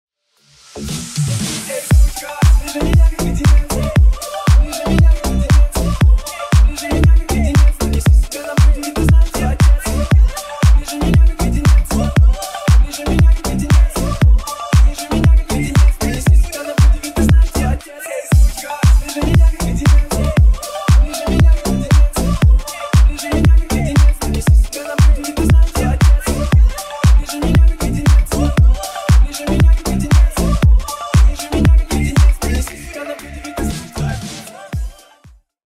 • Качество: 320 kbps, Stereo
Ремикс
клубные
ритмичные
тихие